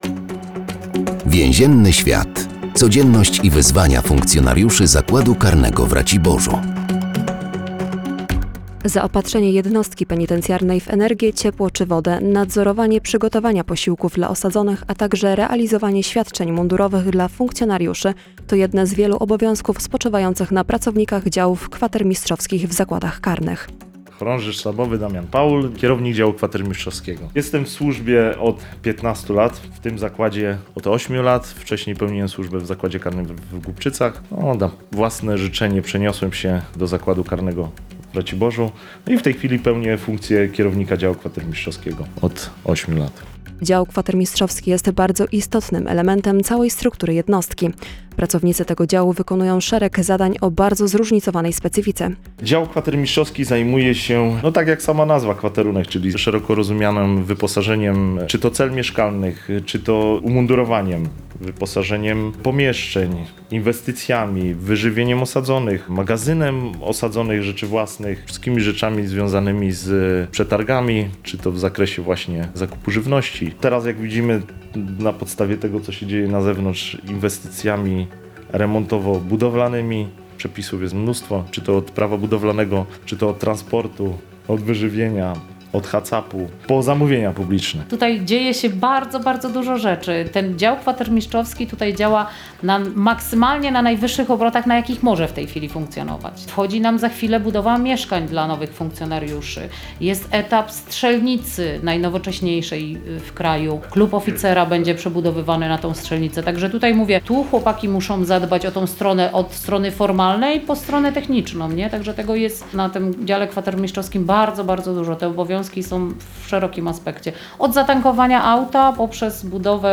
W każdym odcinku audycji “Więzienny świat” funkcjonariusze zakładu karnego w Raciborzu opowiadają o specyfice swojej pracy.